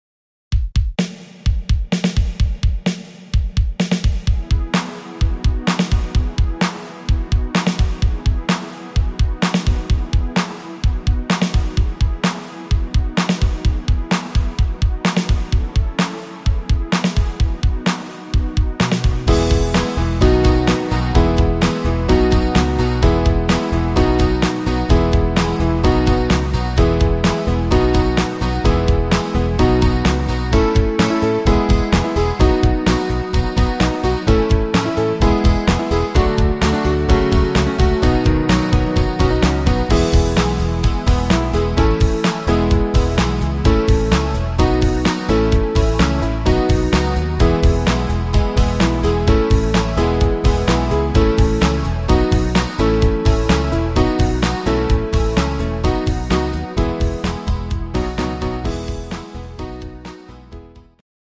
Rhythmus  8 Beat
Art  Oldies, Englisch